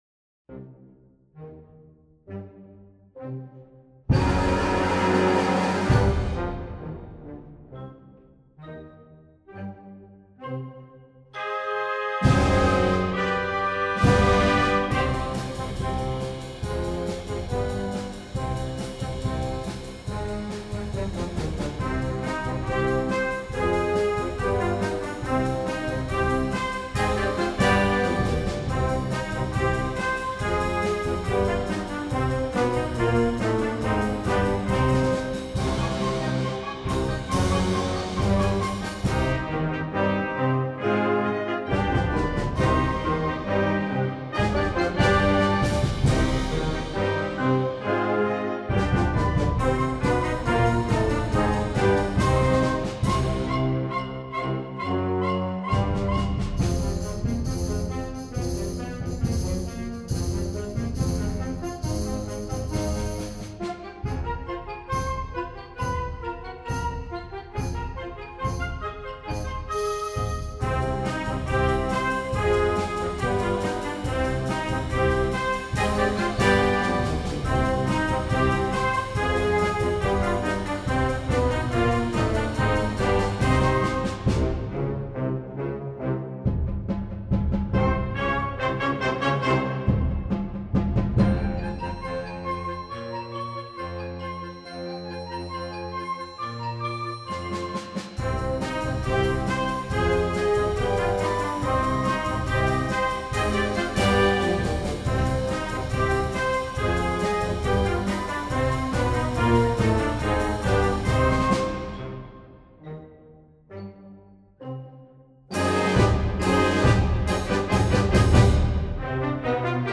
Gattung: Konzertwerk Flexible Band/String Ensemble
Besetzung: Blasorchester